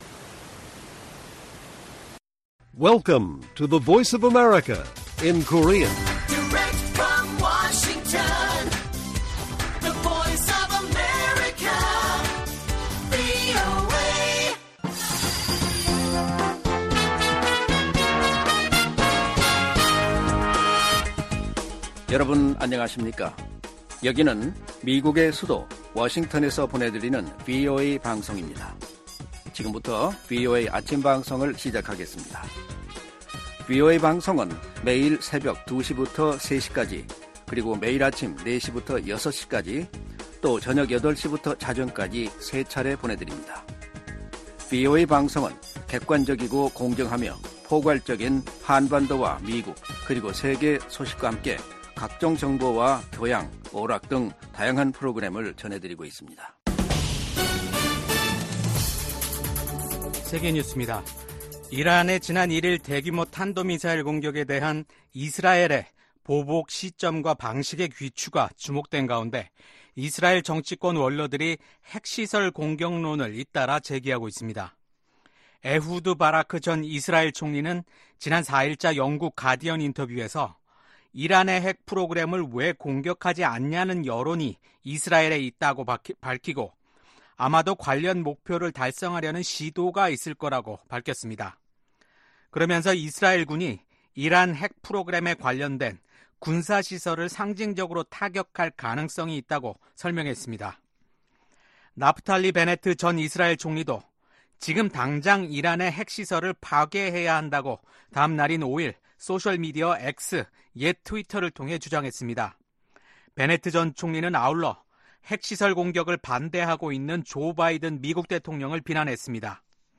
세계 뉴스와 함께 미국의 모든 것을 소개하는 '생방송 여기는 워싱턴입니다', 2024년 10월 8일 아침 방송입니다. 가자지구 전쟁 1년을 맞아 이스라엘과 세계 곳곳에서 이스라엘인 희생자들을 위한 추모 행사가 열렸습니다. 미국 공화당 대선 후보인 도널드 트럼프 전 대통령이 지난 7월 암살 시도 사건이 일어났던 곳에서 다시 유세했습니다.